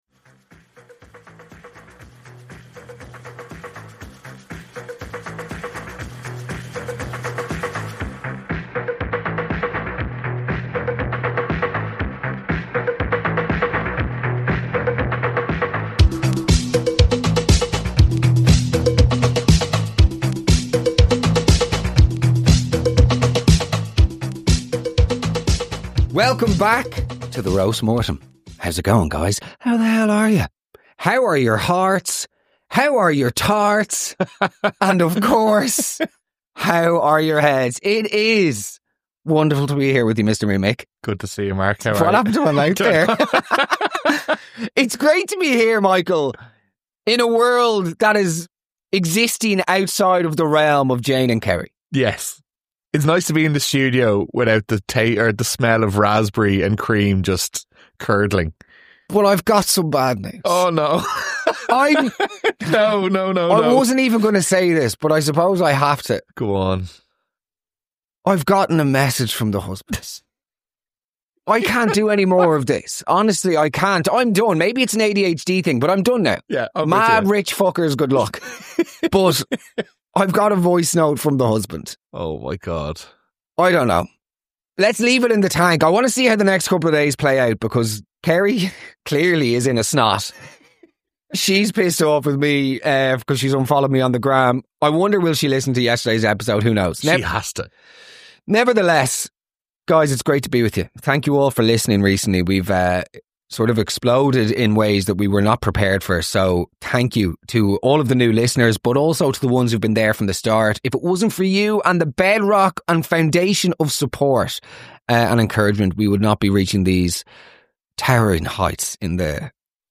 All voices are disguised!